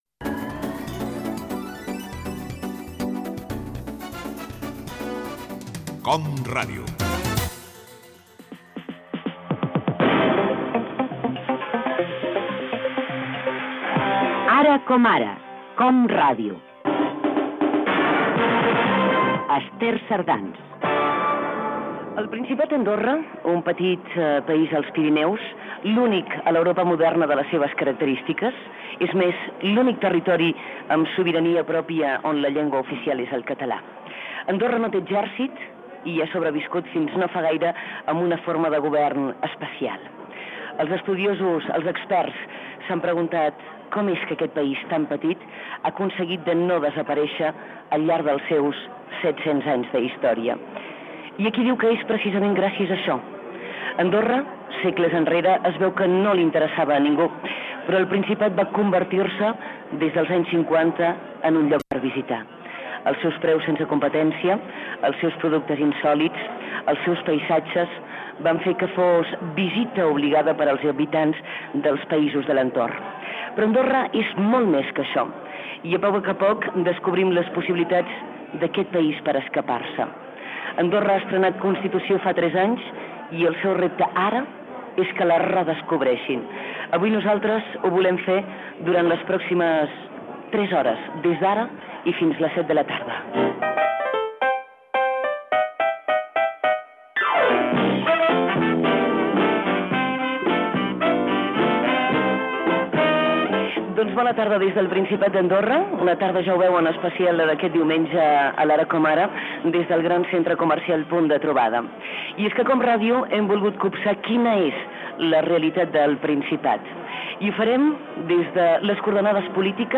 Indicatiu de l'emissora i del programa, presentació i sumari d'un programa especial, fet des del centre comercial "Punt de trobada" a Andorra, tres anys després d'aprovar la seva Constitució.
Entreteniment